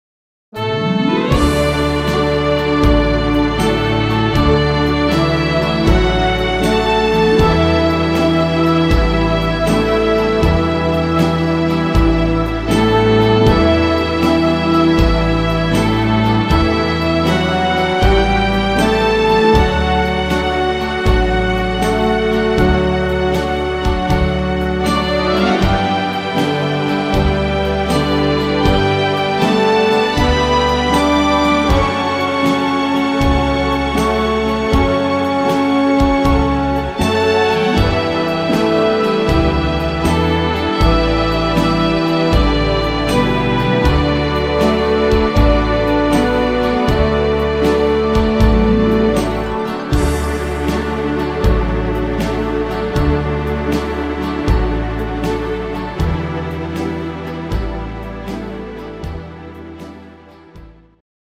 instr.Orchester